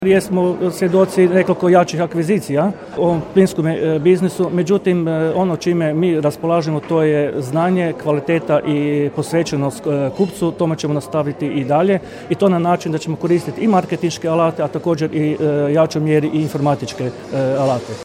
Međimuje plin izborna skupština, Čakovec 17.11.2021.